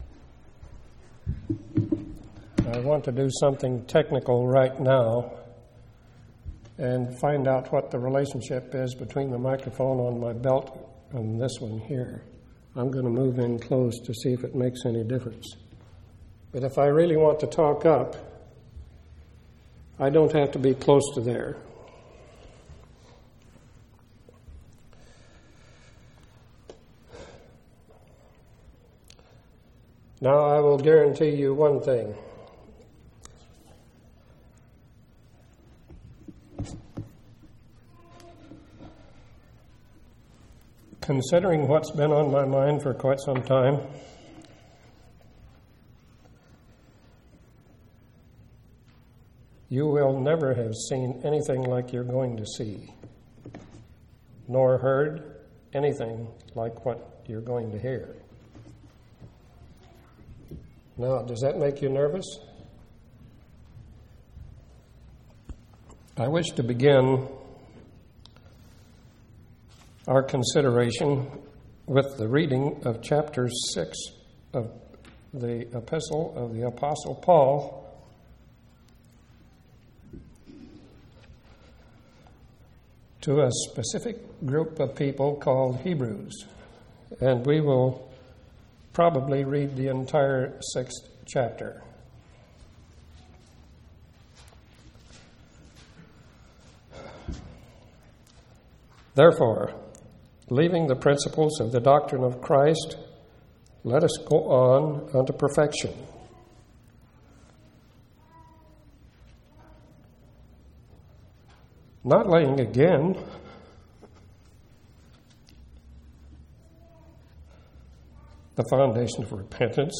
5/22/1994 Location: Temple Lot Local Event